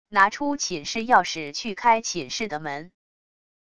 拿出寝室钥匙去开寝室的门wav音频